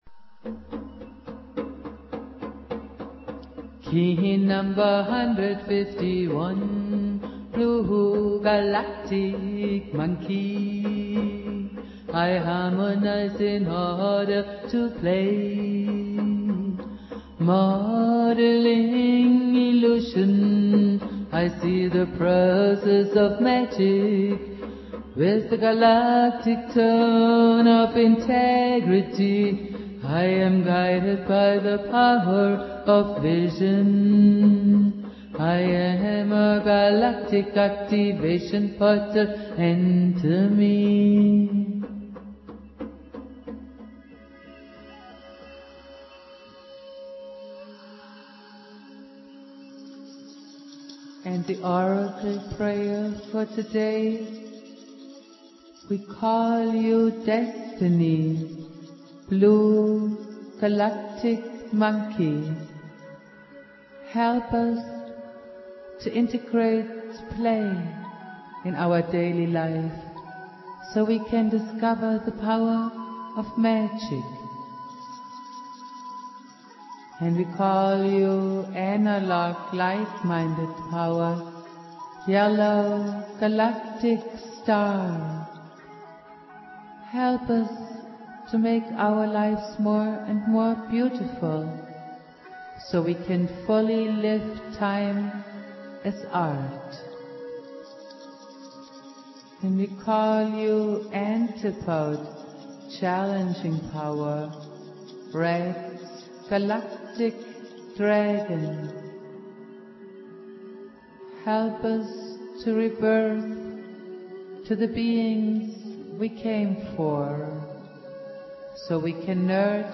with Jose Arguelles - Valum Votan playing flute.
Prayer